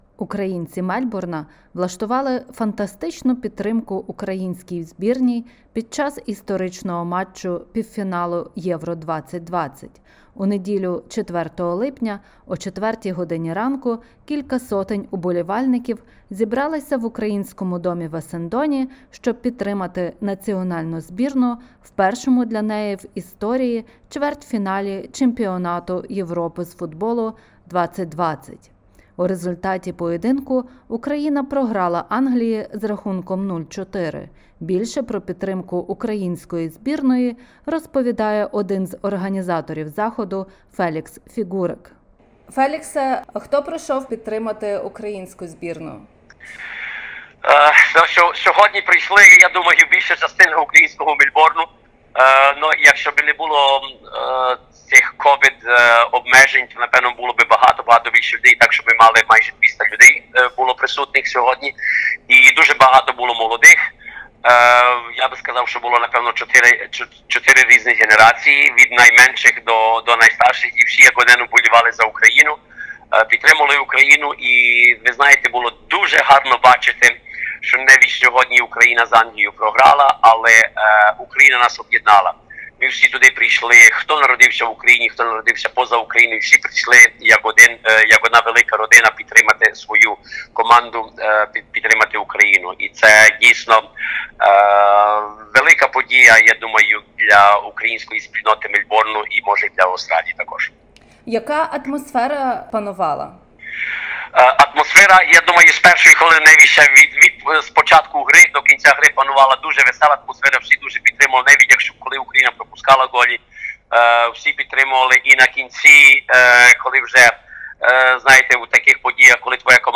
У неділю 4 липня о 4 годині ранку, кілька сотень уболівальників зібралися в Українському домі в Есендоні, щоб підтримати національну збірну в першому для неї в історії чвертьфіналі чемпіонату Європи з футболу 2020 року.